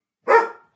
dog-1.mp3